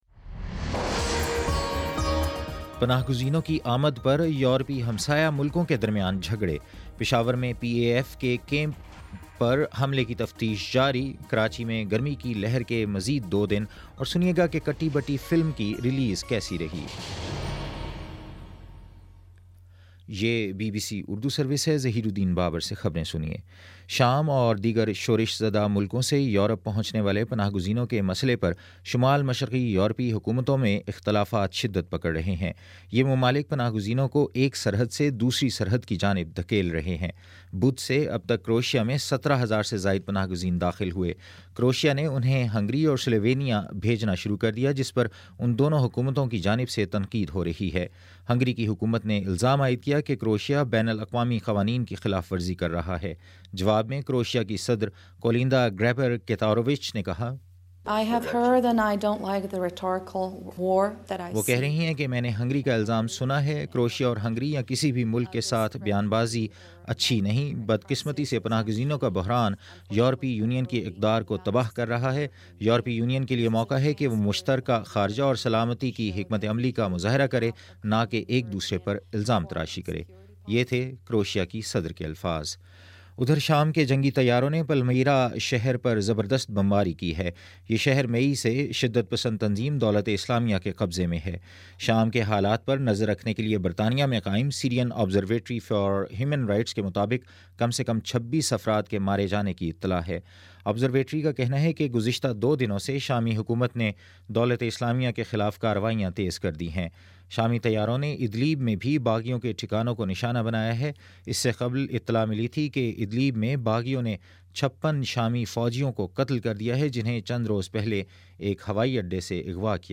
ستمبر19 : شام پانچ بجے کا نیوز بُلیٹن